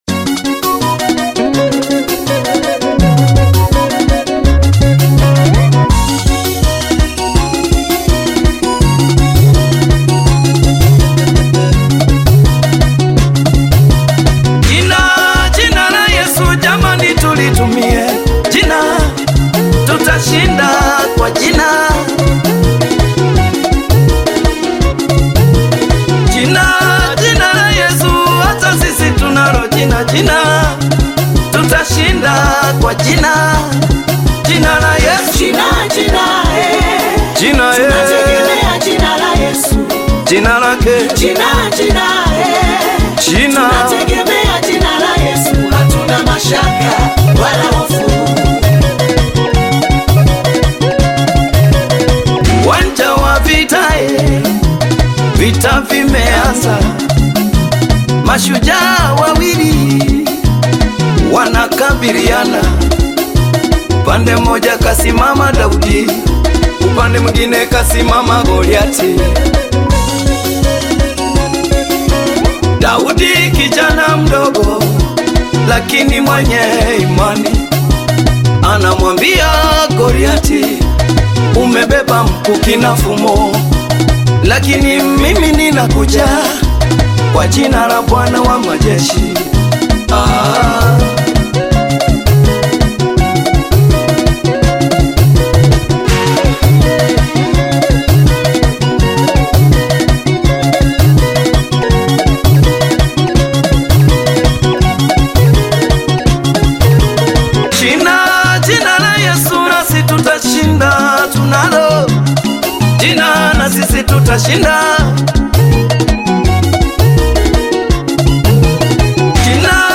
Gospel music track
Tanzanian Gospel artist, singer and songwriter